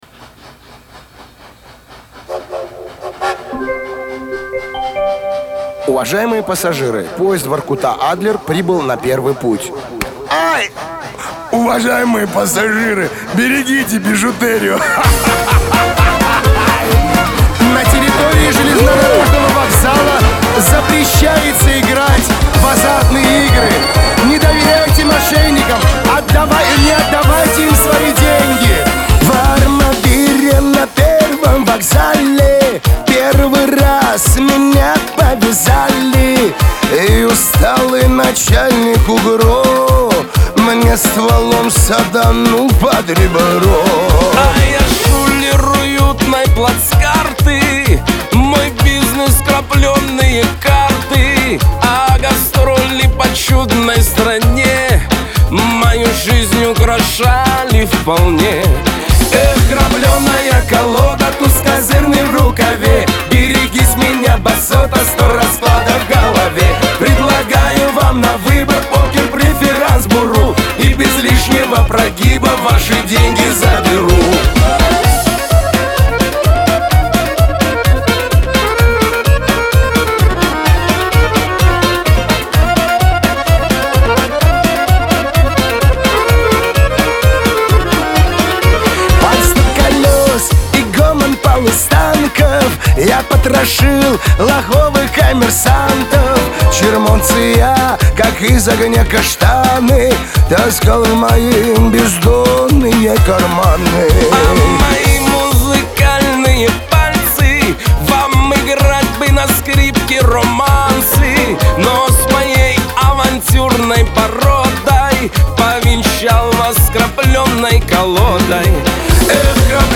дуэт
Лирика
Шансон